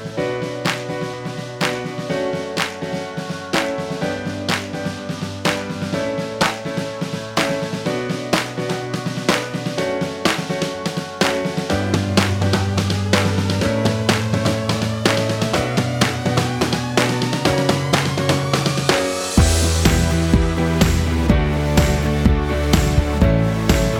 no Backing Vocals at all Pop (2010s) 3:54 Buy £1.50